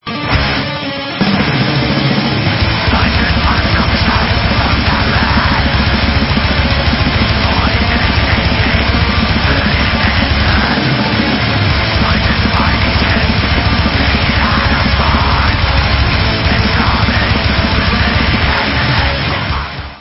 + 15 YEARS OF SATANIC BLACK METAL // 2008 ALBUM